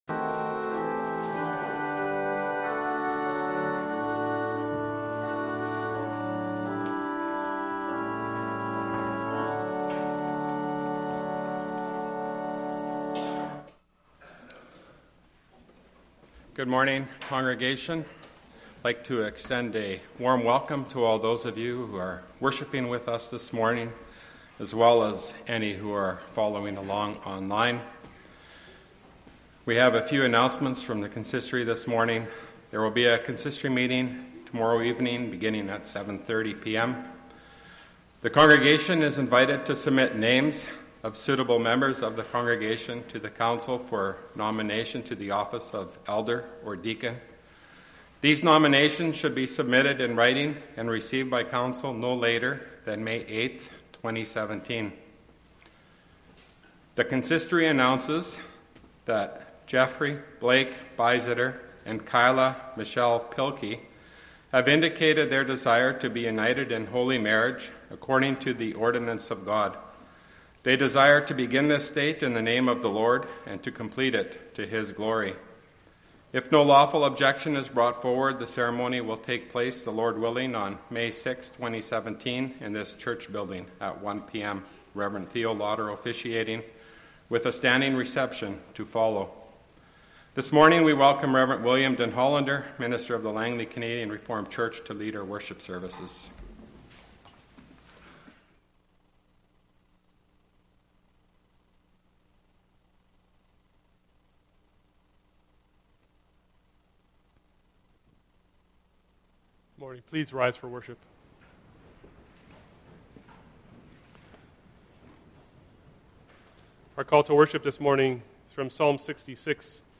Public Reading of Holy Scripture
Service Type: Sunday Morning